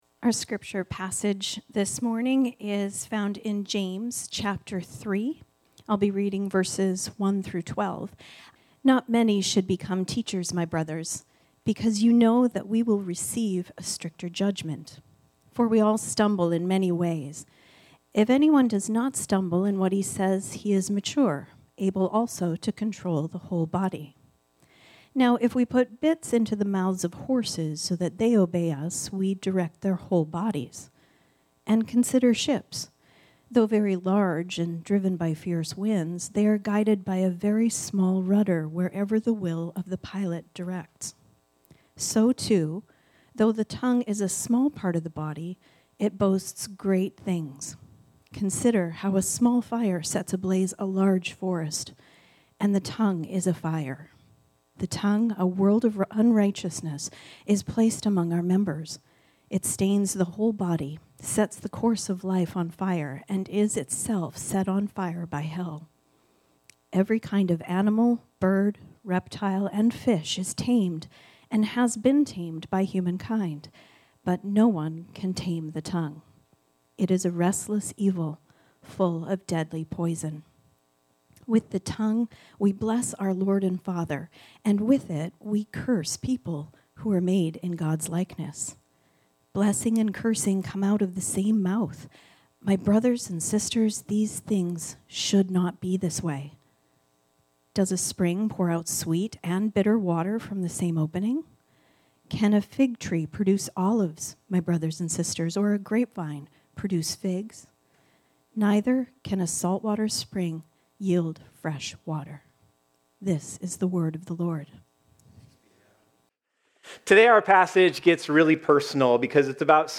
This sermon was originally preached on Sunday, November 2, 2025.